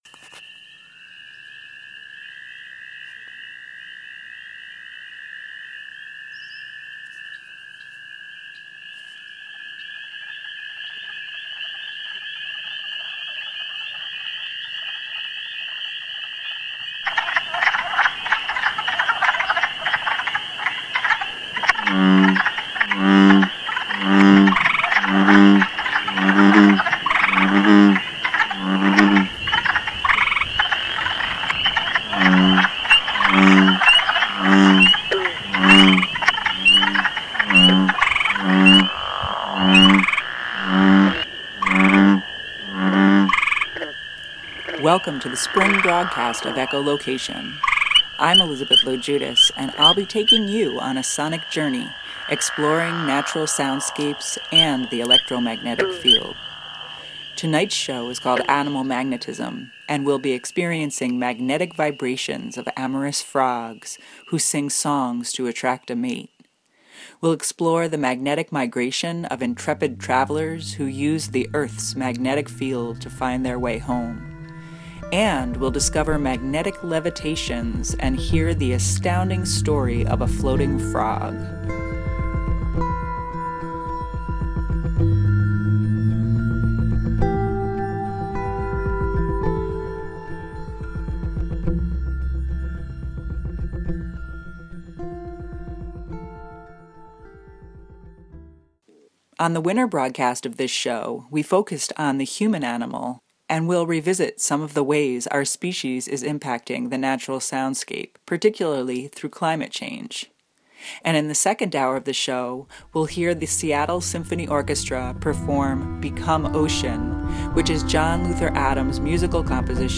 Magnetic vibration ~ listen to the calls of amorous frogs that fill the night soundscape with song. Magnetic migration ~ investigate intrepid animal migrants that use the Earth’s magnetic field to find their way home. Magnetic levitation ~ hear the astounding story of frogs made to levitate using a giant magnetic field. The show will feature an Echolocator Profile, a report on People’s Climate March events in the Hudson Valley, and the simply stunning sounds of spring.